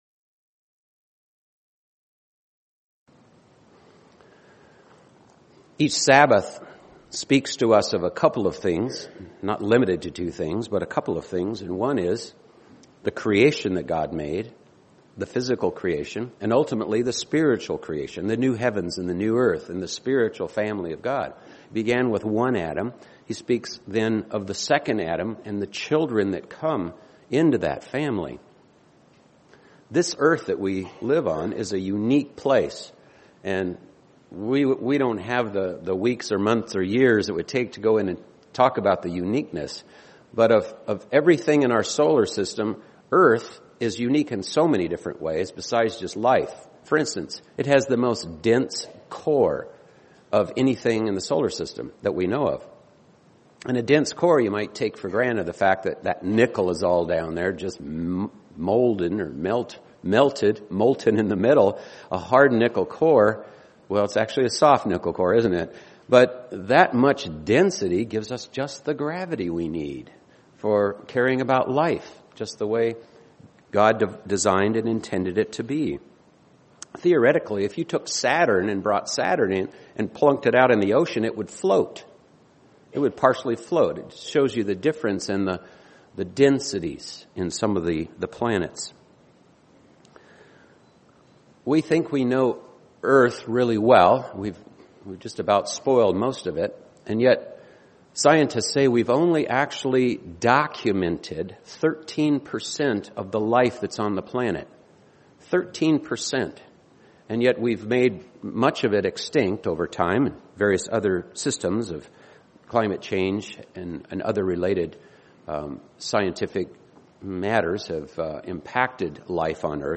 We are judged by them and must be good stewards of our tongues, UCG Sermon Transcript This transcript was generated by AI and may contain errors.